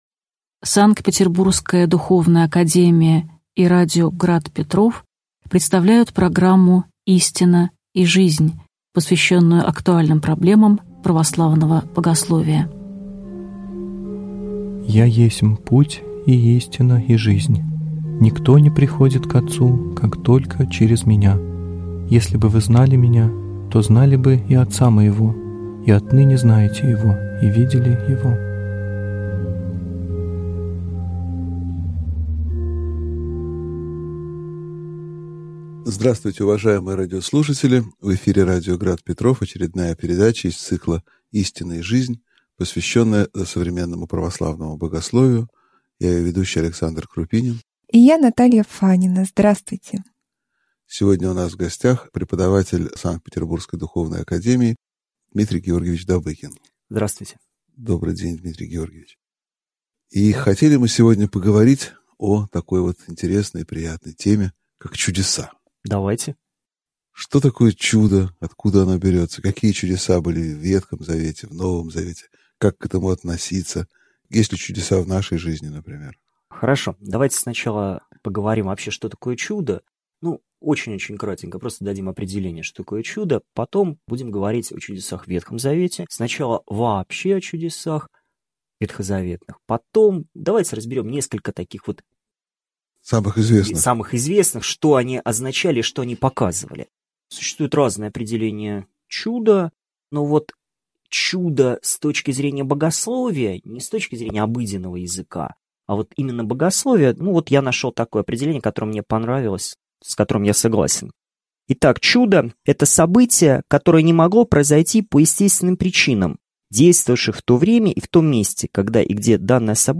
Аудиокнига Чудеса в Ветхом и Новом Заветах (часть 1) | Библиотека аудиокниг